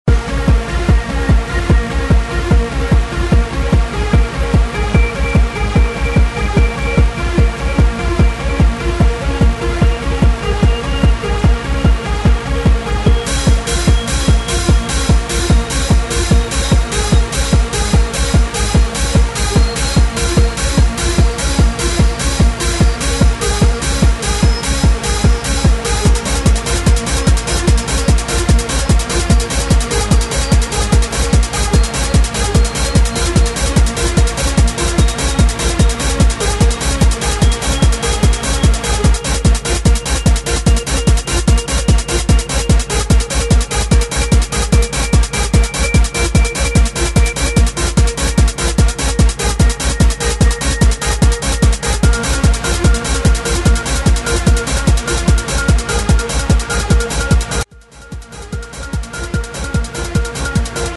Género: Electronic
Estilo: Hard TranceTrance